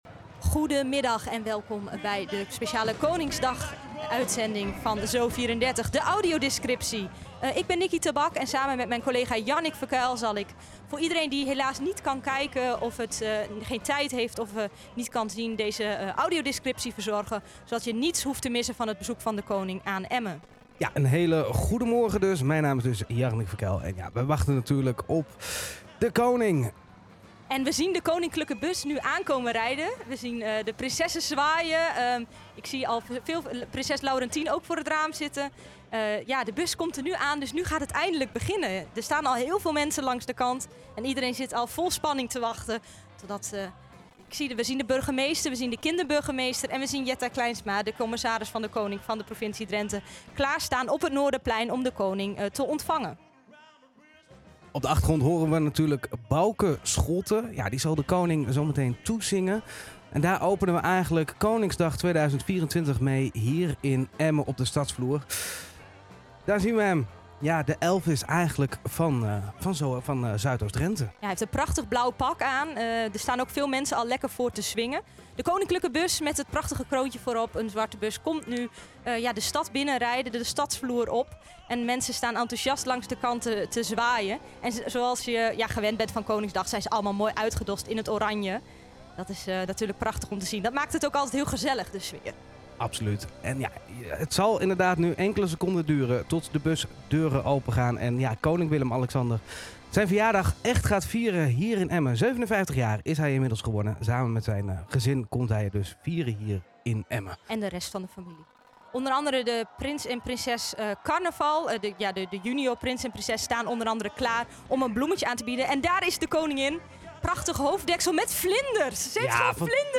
Audiodescriptie bij ZO!34: een stap verder in toegankelijkheid - NLPO
Luister naar een impressie van de uitzending.
Koningsdag-Audiodescriptie-voorbeeld.mp3